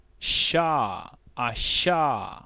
42 ʃ consonant fricative post-alveolar unvoiced [
voiceless_postalveolar_fricative.wav